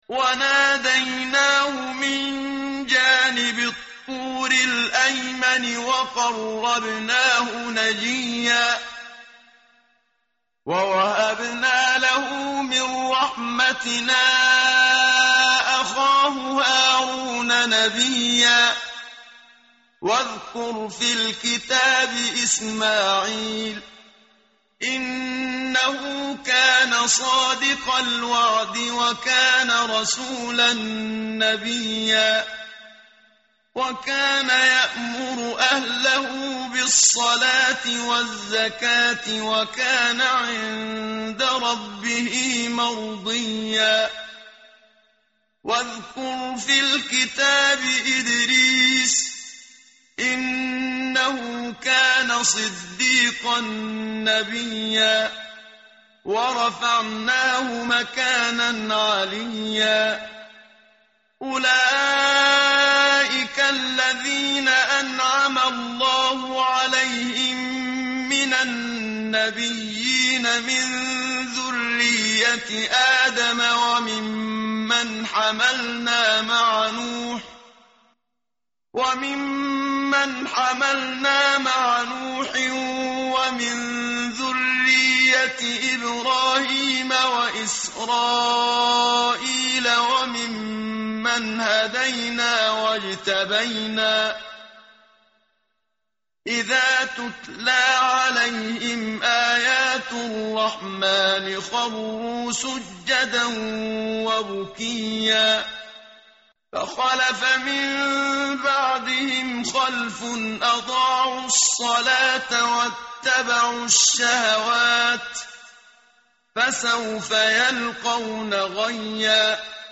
tartil_menshavi_page_309.mp3